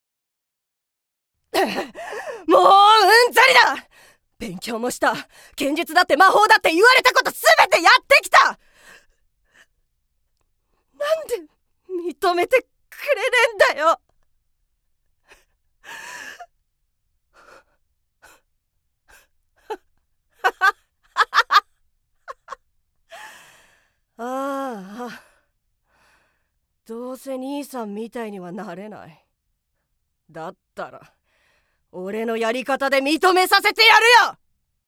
◆少年◆